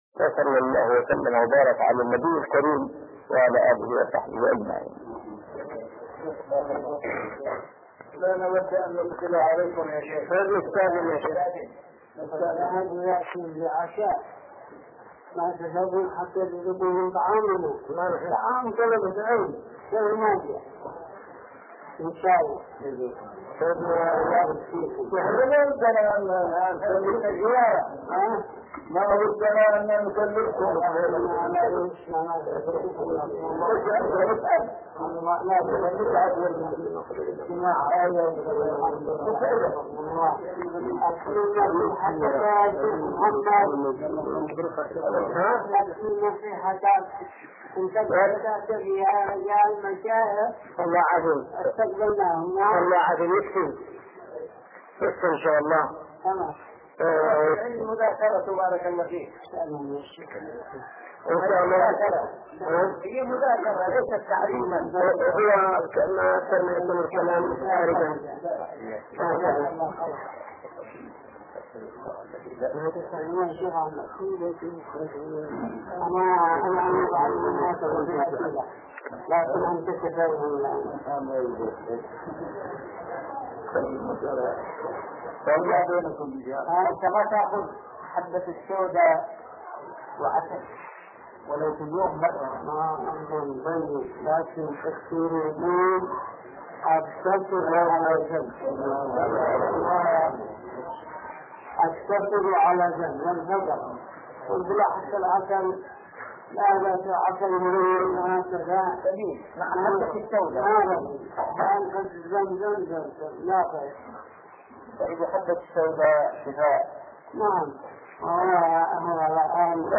محاضرة لقاء نادر بين ثلاثة علماء أكابر الشريط 2 الشيخ مقبل بن هادي الوادعي